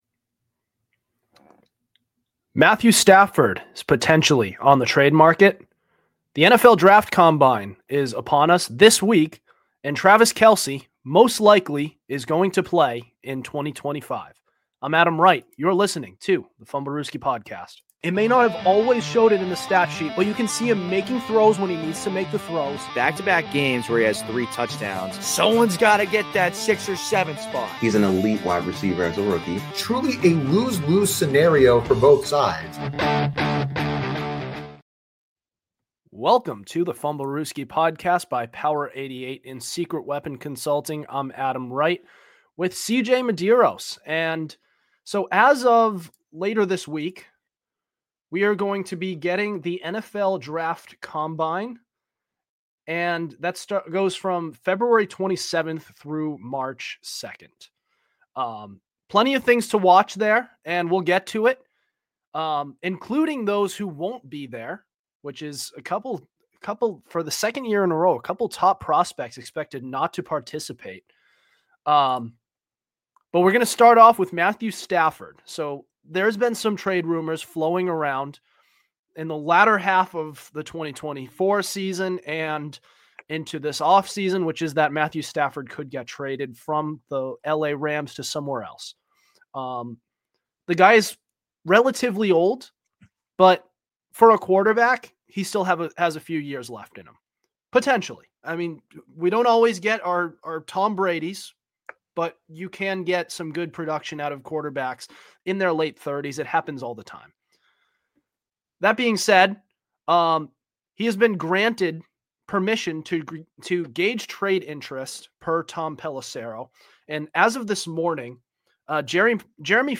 An NFL podcast where we discuss all things pro-football-related, including recent news and hot takes. Hosted by four college guys